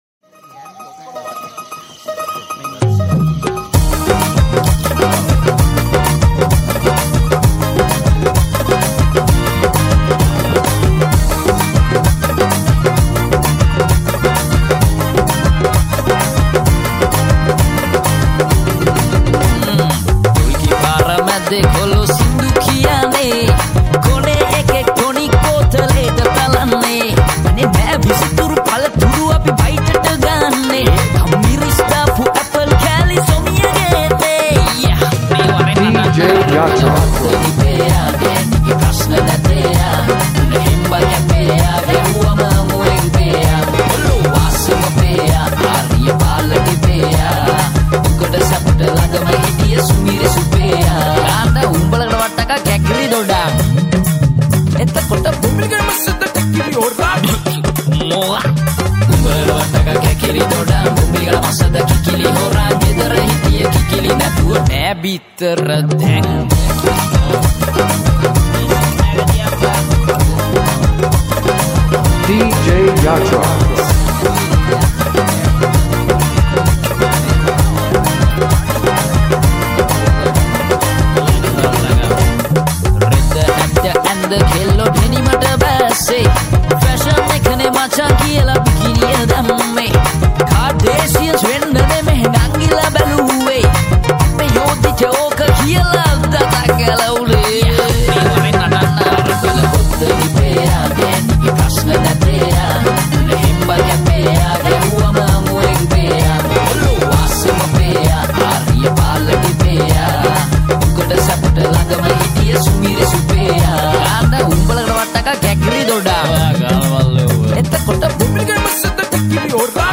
High quality Sri Lankan remix MP3 (3.3).